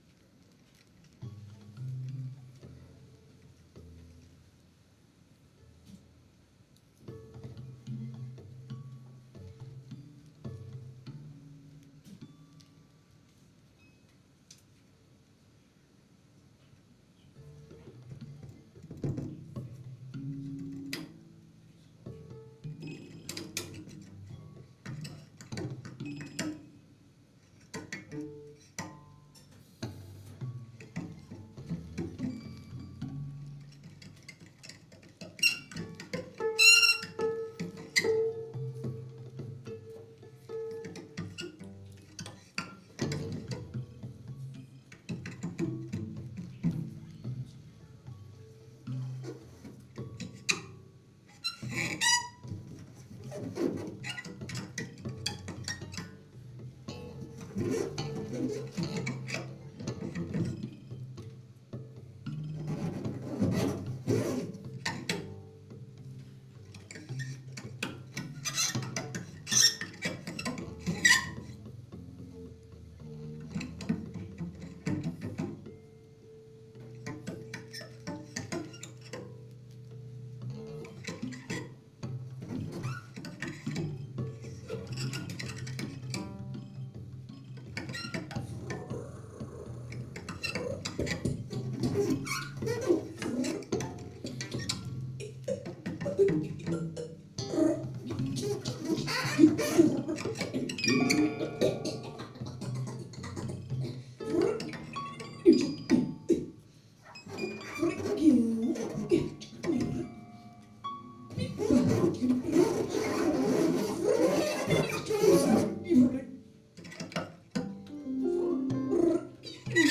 improvising cellist
guitar
voice.